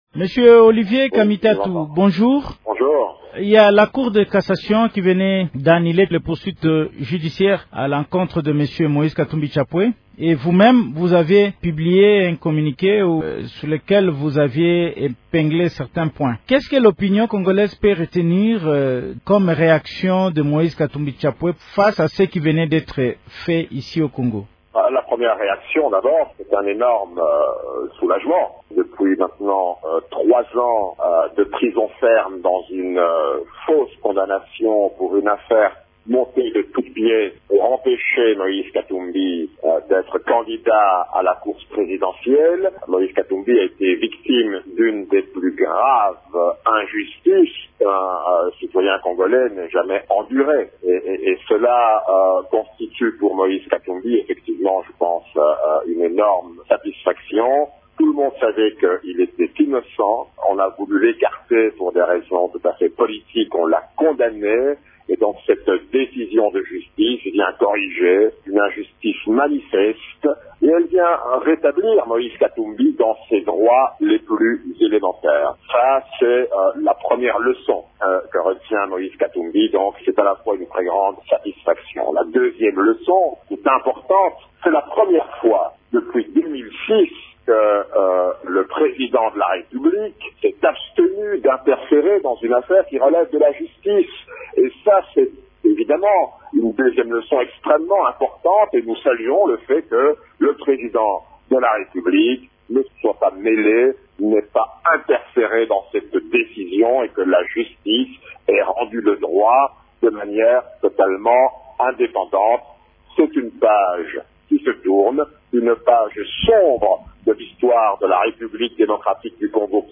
Intervenant sur Radio Okapi, il a affirmé son soulagement :